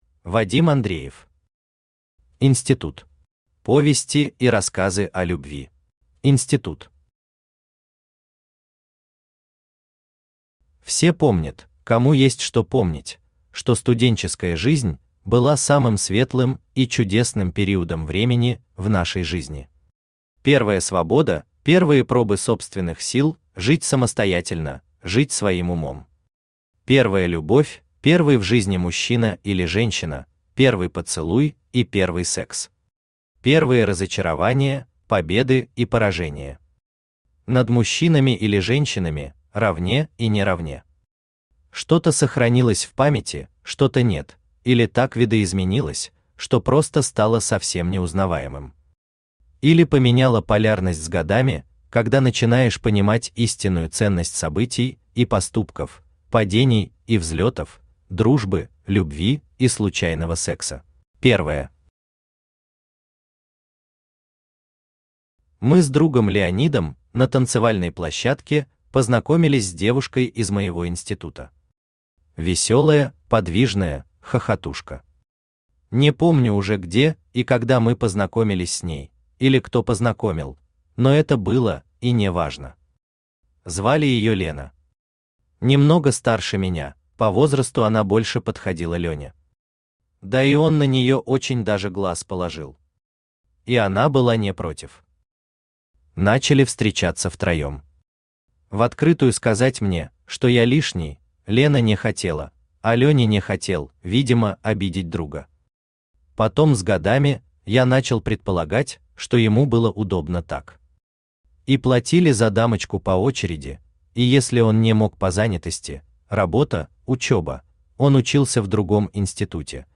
Аудиокнига Институт.
Aудиокнига Институт. Повести и рассказы о любви Автор Вадим Андреев Читает аудиокнигу Авточтец ЛитРес.